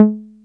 303 F#3 3.wav